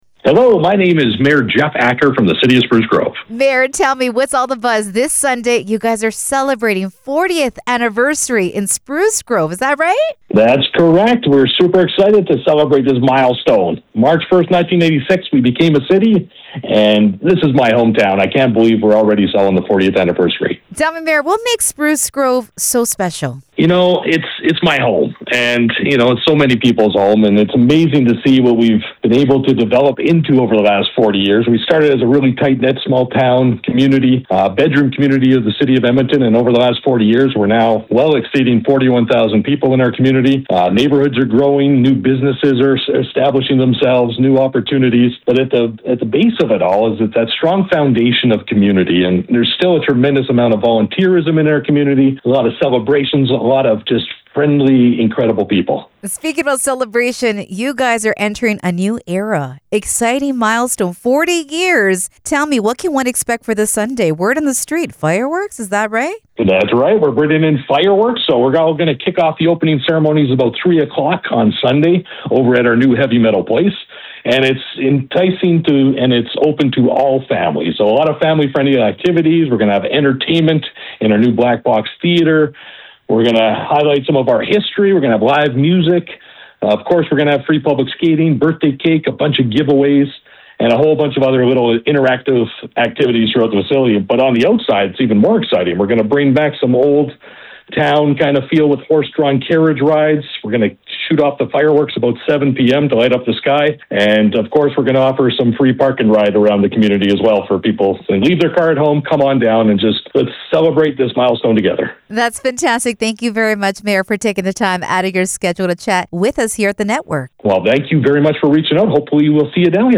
Conversation with Mayor of Spruce Grove, Jeff Acker
mayor-jeff-acker-of-spruce-grove-web.mp3